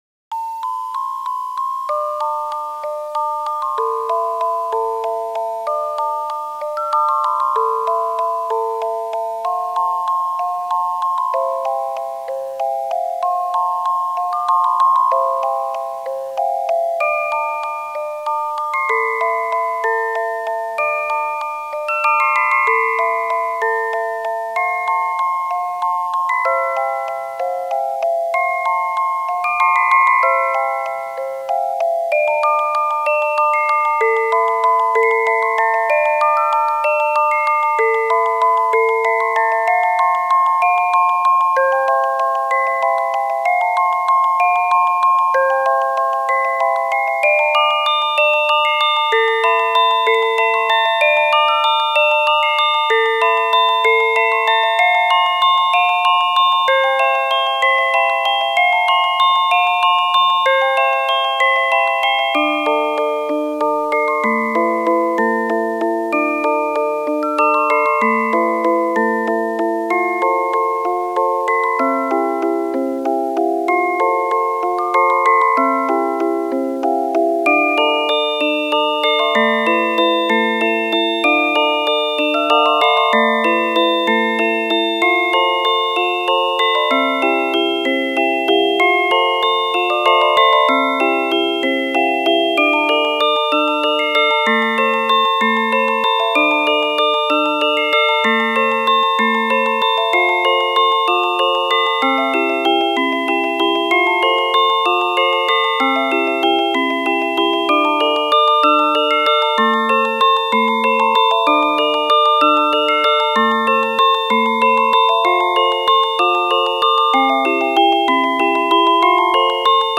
Yet another music box, an MSP430 based music box.